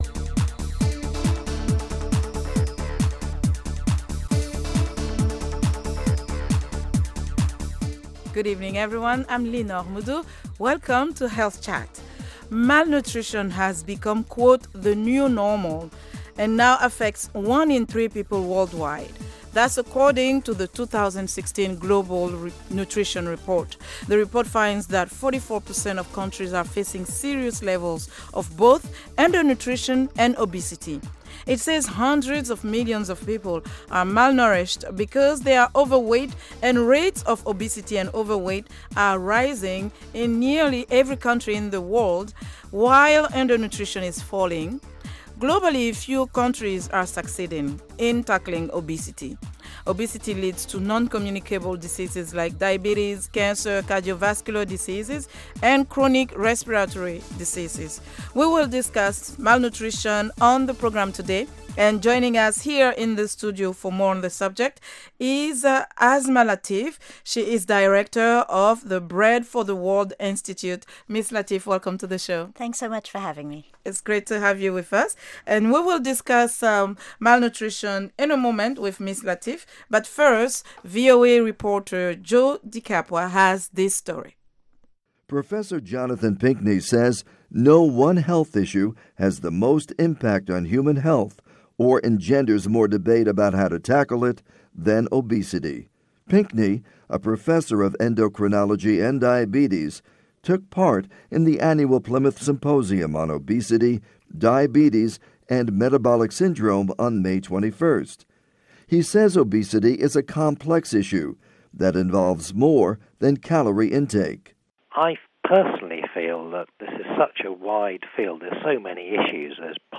Health Chat is a live call-in program that addresses health issues of interest to Africa. The show puts listeners directly in touch with guest medical professionals. It includes a weekly feature spot, health news and comments from listeners on health issues.